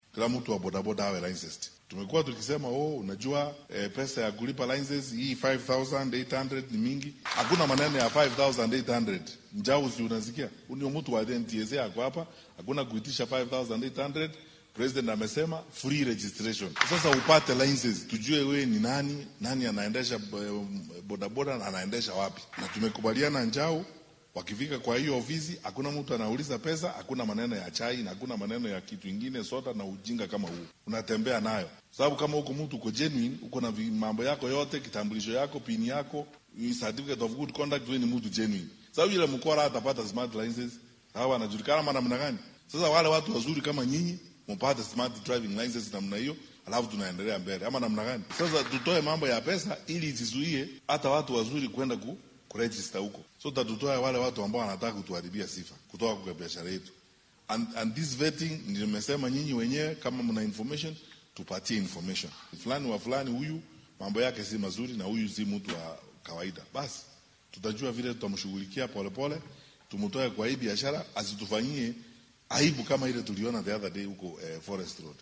Xilli uu maanta magaalada Nairobi gaar ahaan xarunta shirarka caalamiga ee KICC uu kulan kula qaatay wadayaasha dhugdhugleyda ayuu wasiir Matiang’i xusay in dowladda dhexe ay soo saartay guddi gaar ah oo qaabilsan isku dubaridka howshan.